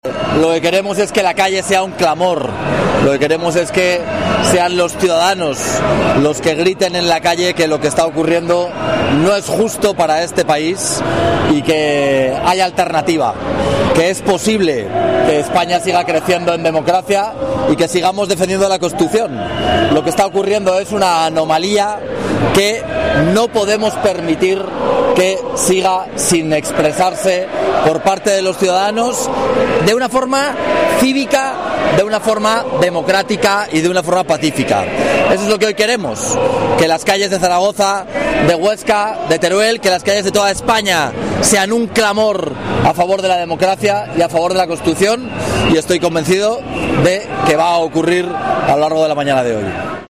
Declaraciones de Jorge Azcón en la concentración del PP convocada en Zaragoza.